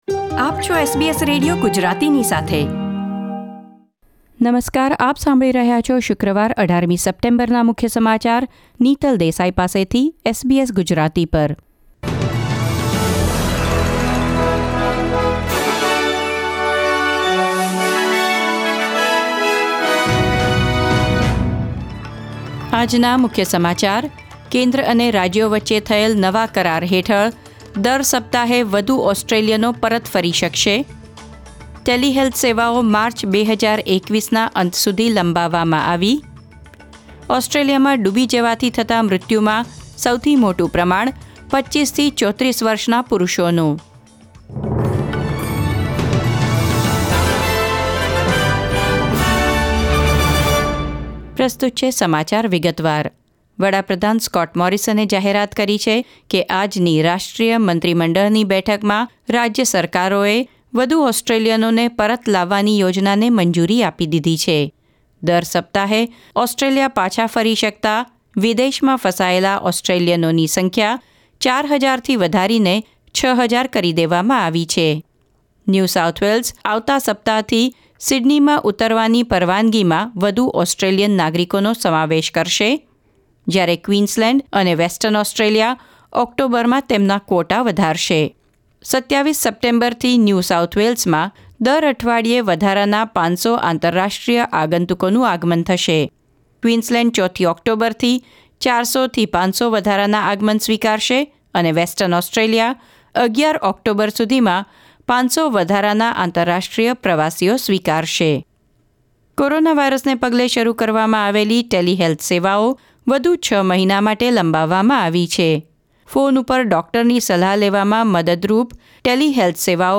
SBS Gujarati News Bulletin 18 September 2020